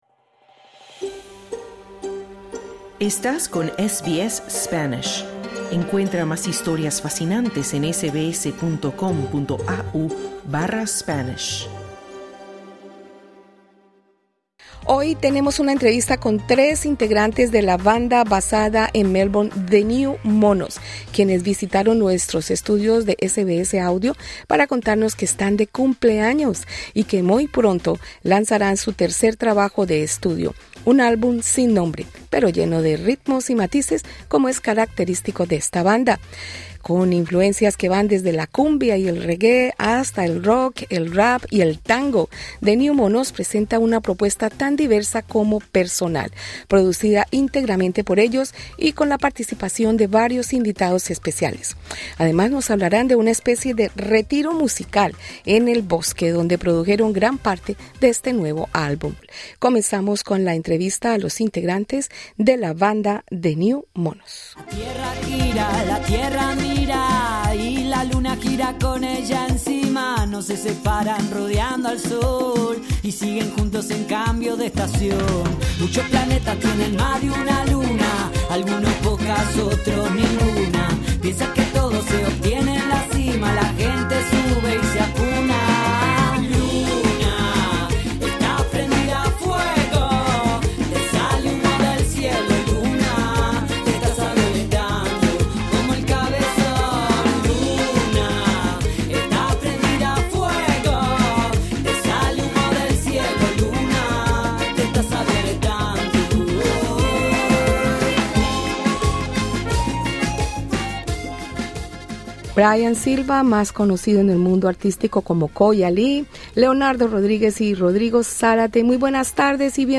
Tres integrantes de la banda basada en Melbourne, The New Monos, visitaron nuestros estudios en Melbourne y hablaron de su tercer álbum, con el que celebran los 10 años de creación de esta agrupación musical.